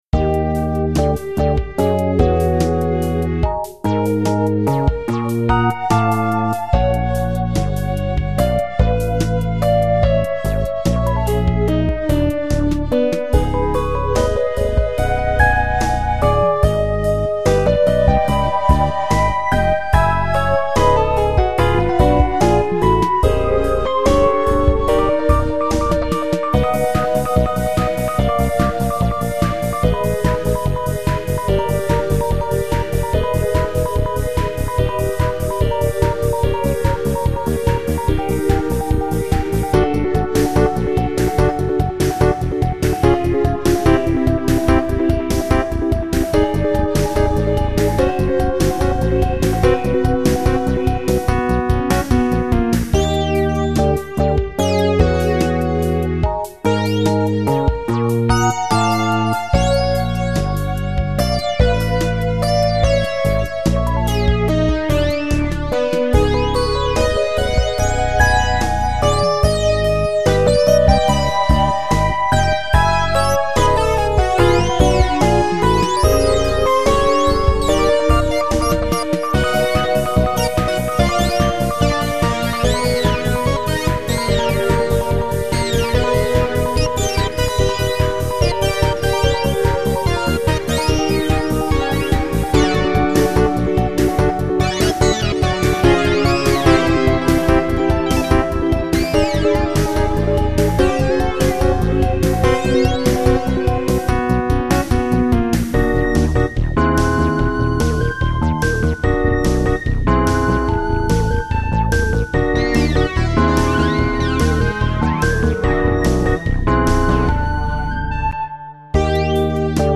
I kept with 4/4 timing to keep some coherency. Right now it has no real intro and it has no ending–it’s a loop for now, and there’s obviously no vocals on it right now…
No vocals, just an instrumental.
let us know if you do more with this one. funky micro-cheese to the max!
I like the track, sounds like some experimental stuff on a breakbeat cd i own. :)